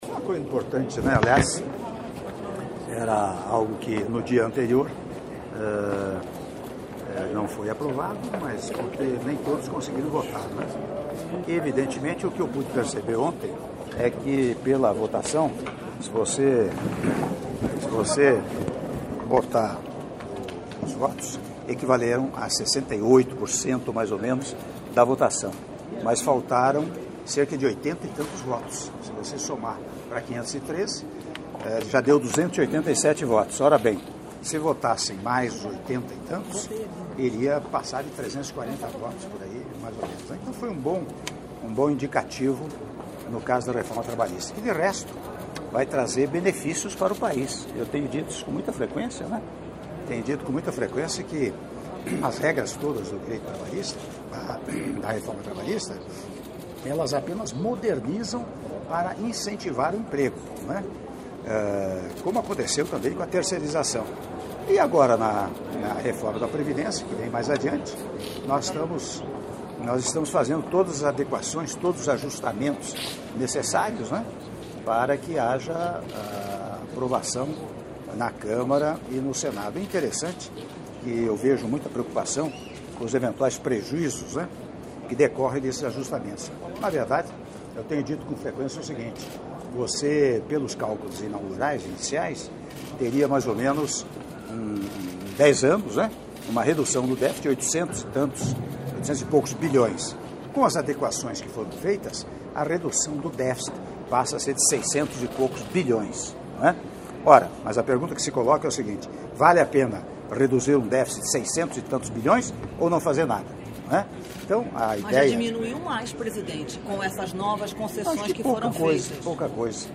Áudio da entrevista coletiva concedida pelo Presidente da República, Michel Temer, após almoço em homenagem aos formandos do Instituto Rio Branco - (05min59s) - Brasília/DF — Biblioteca